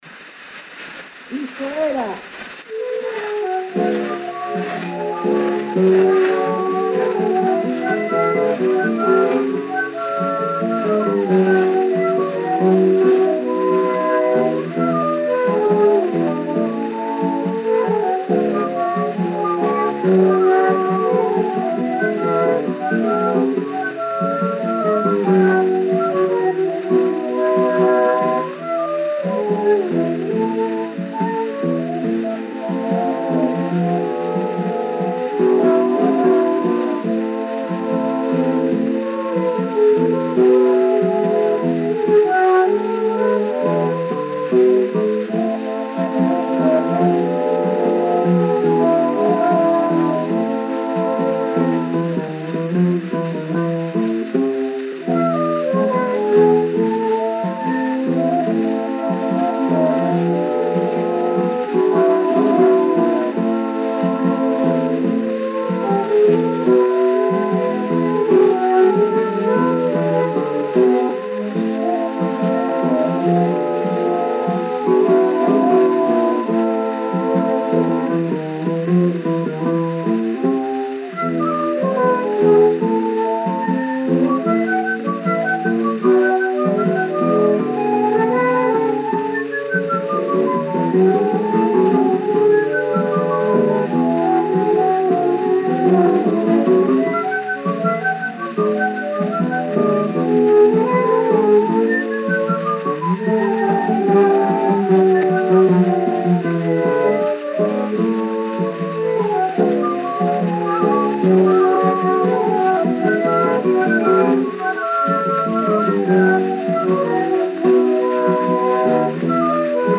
Gênero: Tango argentino.